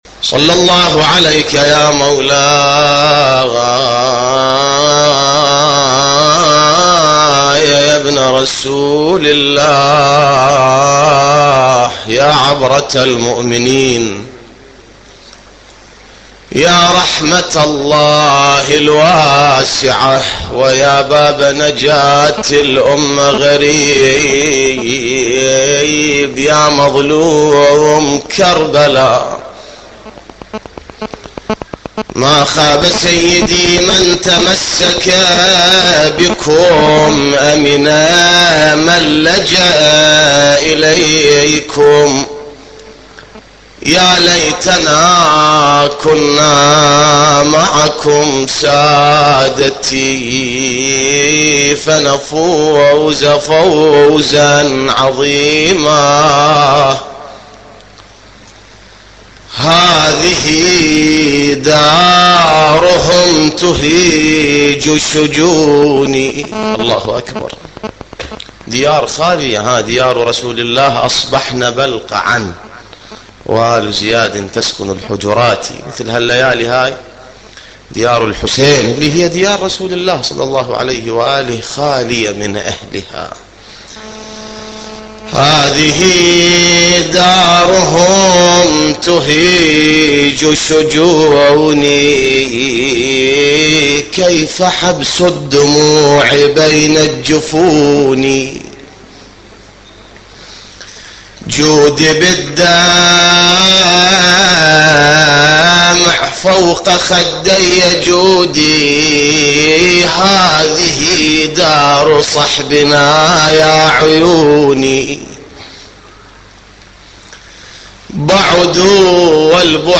نواعي حسينية 1